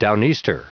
Prononciation du mot down-easter en anglais (fichier audio)